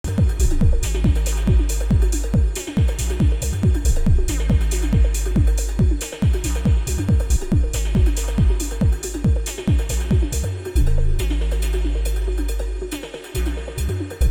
To be honest, sometimes it’s worse, but here’s a bunch of snippets I just recorded, taken from my last improv practice session.
Hearing it back it’s less worse then I sometimes imagine, but it does have this barrage of short staccato 16ths constantly.